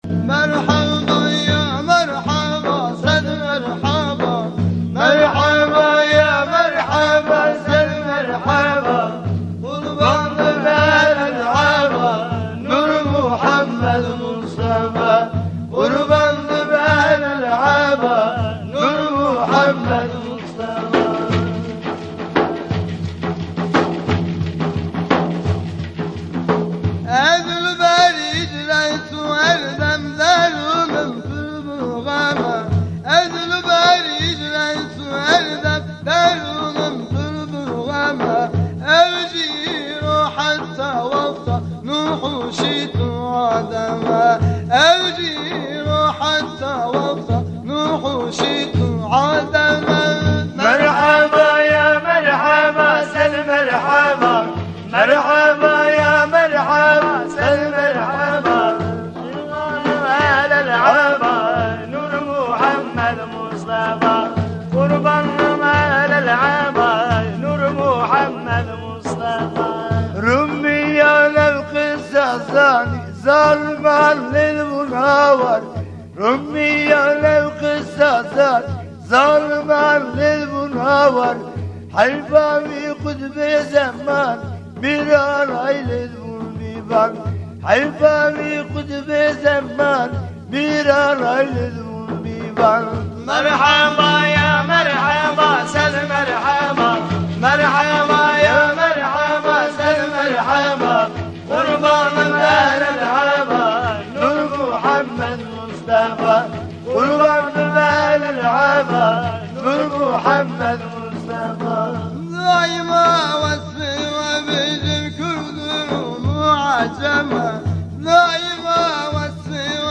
Etiketler: şanlıurfa, Tasavvuf